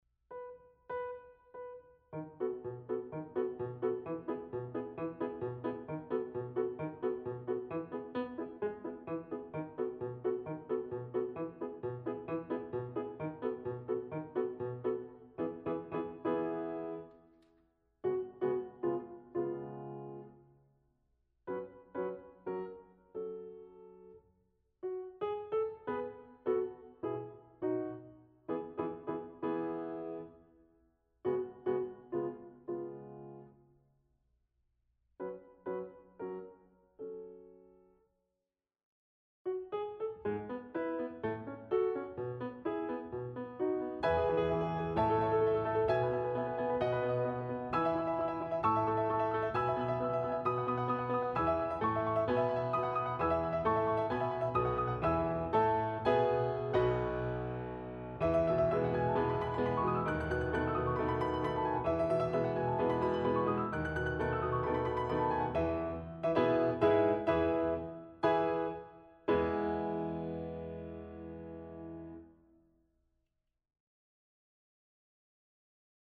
Répertoire pour Mezzo-soprano/alto